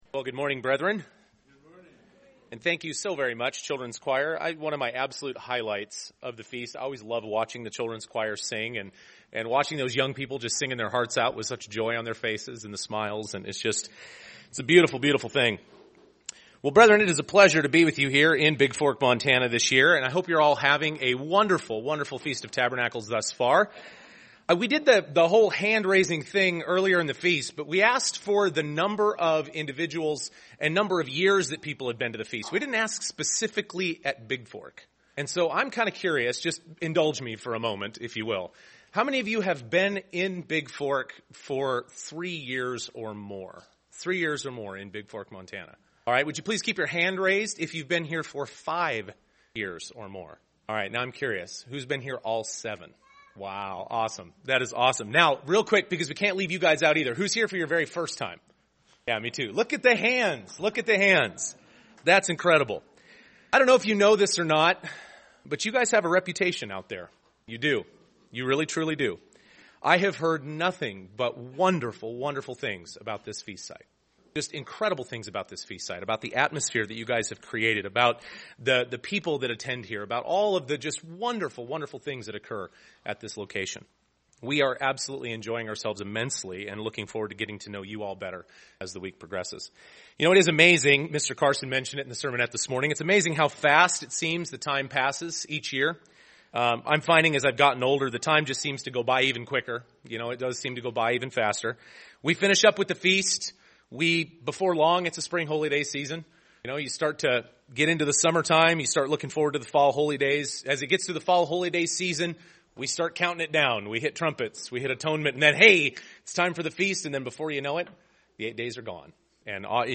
This sermon was given at the Bigfork, Montana 2016 Feast site.